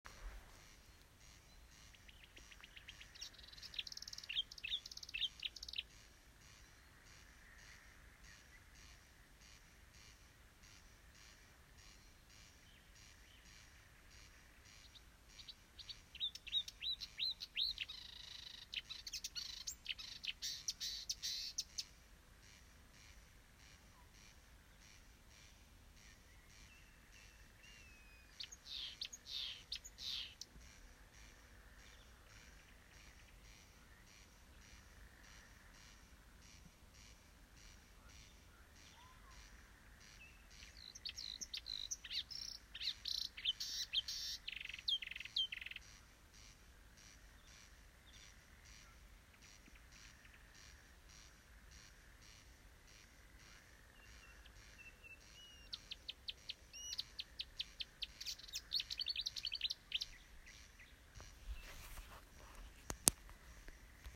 Птицы -> Славковые ->
болотная камышевка, Acrocephalus palustris
СтатусПоёт